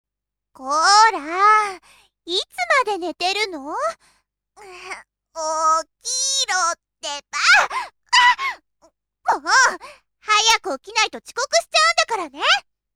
旧サンプルボイスは音質は現在と異なりますが、声質のご参考になれば幸いです。
マイク：BETA 58A（SHURE） サウンドプロセッサー：SE-U55GX（ONKYO）
▼ サンプルボイス・女
arrow お隣に住む幼なじみ 明るく元気。高校生くらい。
female_m1.mp3